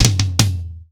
TOM     3C.wav